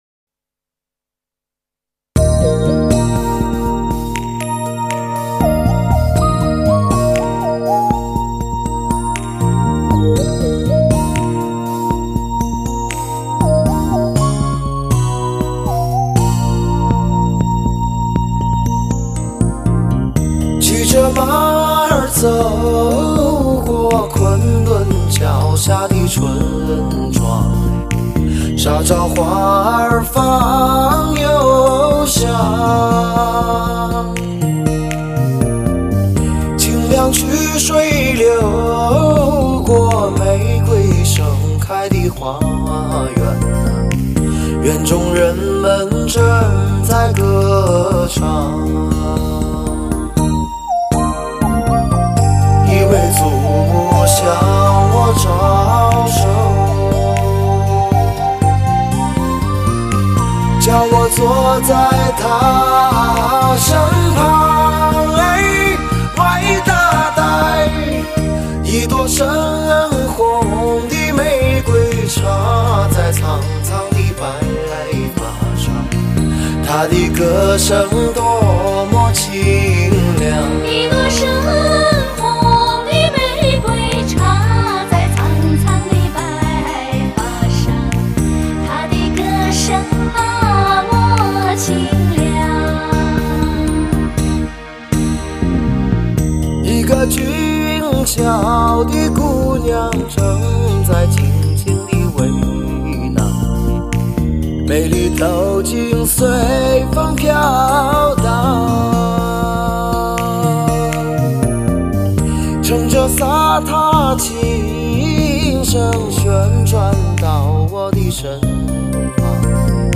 他的声音豪迈不羁，熟悉中蕴含着感动，宏韵中参杂着细腻，狂野中酝酿着沧桑，游离中沉淀着坚毅！